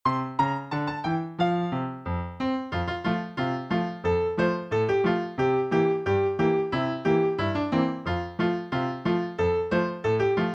Listen to a sample of the instrumental track
includes piano solo mp3 and lyrics pdf.